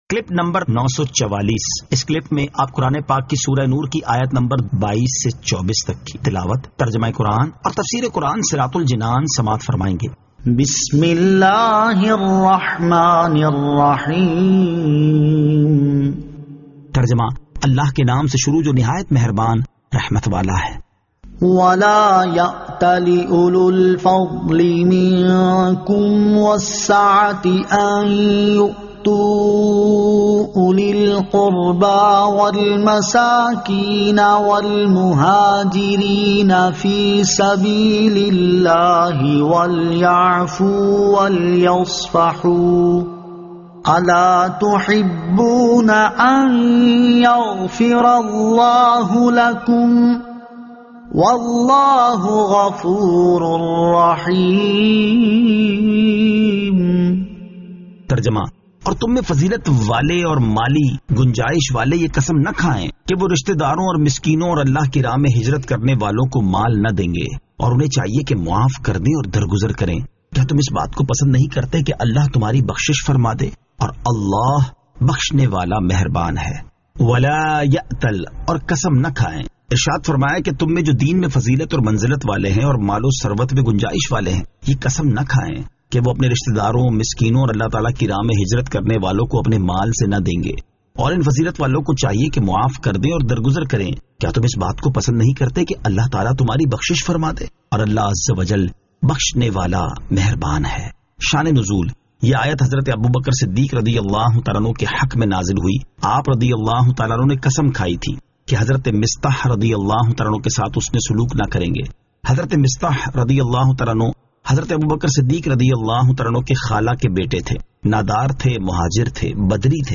Surah An-Nur 22 To 24 Tilawat , Tarjama , Tafseer